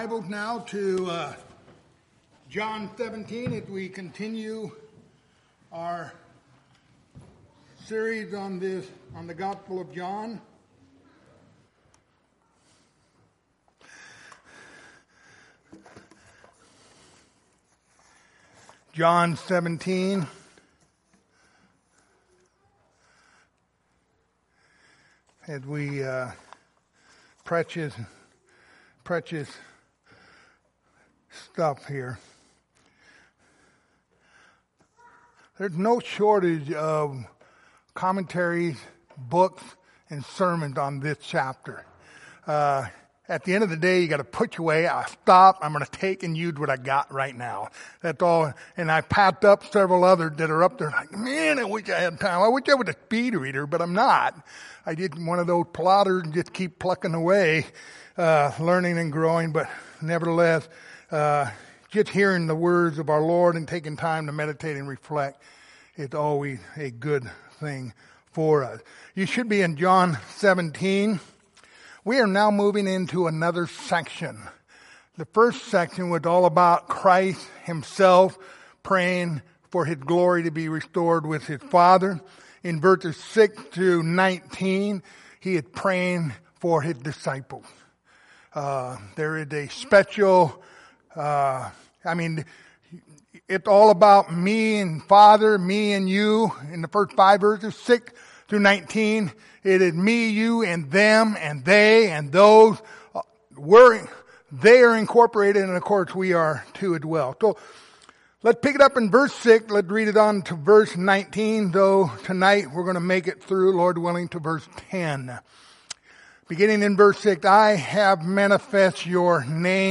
Passage: John 17:6-19 Service Type: Wednesday Evening